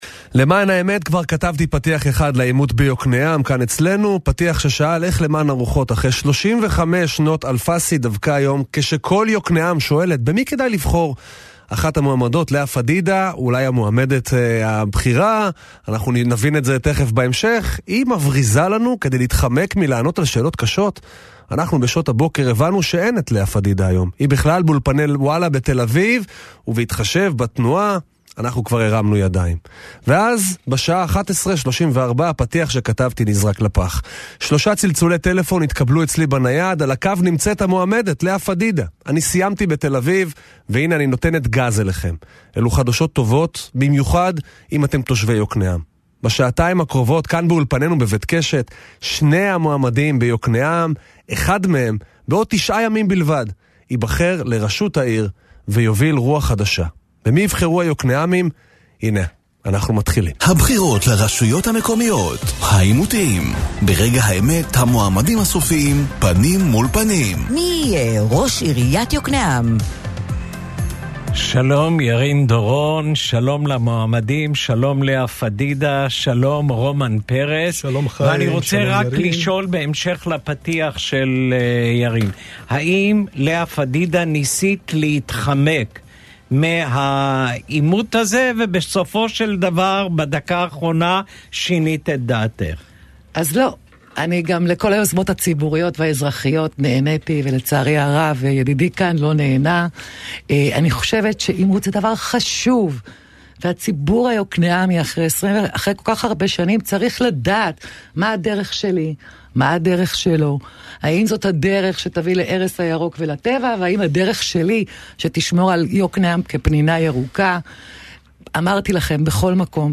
העימותים | עיריית יוקנעם - רדיו קול רגע
לעימות שנערך באולפני הרדיו הגיעו בסופו של דבר שני המועמדים שבמשך שעתיים התעמתו על הנושאים הבוערים ולמרות שלחצו בסיום העימות נראה שהשבוע הקרוב יהיה סוער במיוחד